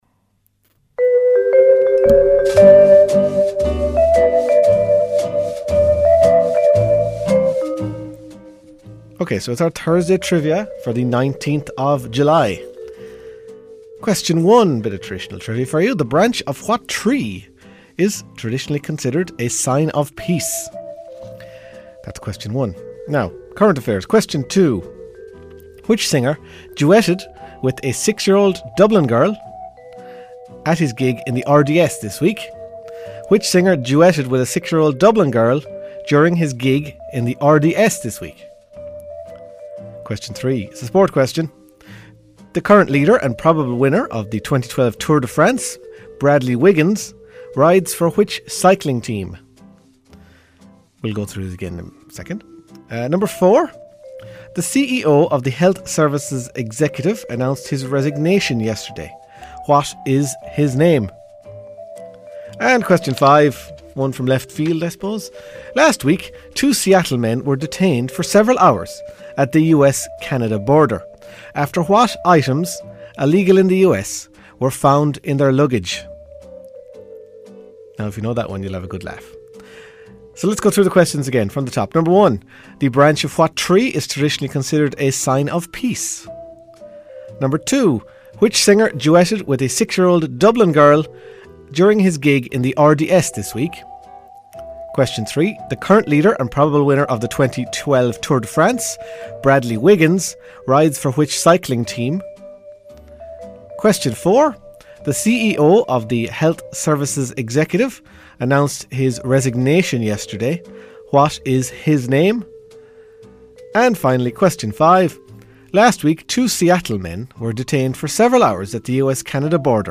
Five trivia questions from the Good Morning Show on Claremorris Community Radio